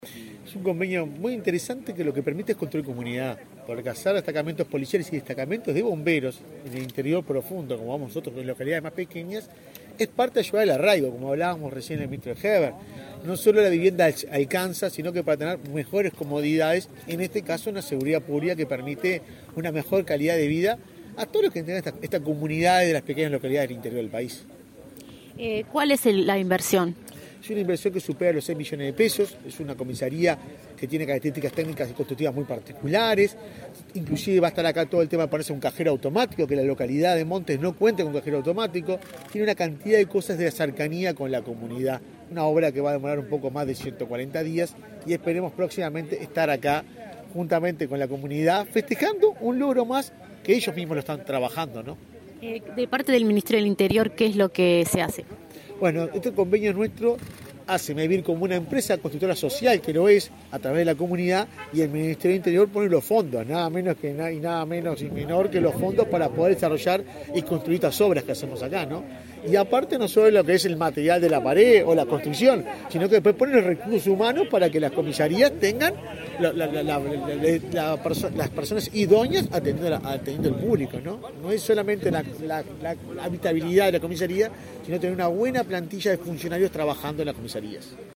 Declaraciones del presidente de Mevir a la prensa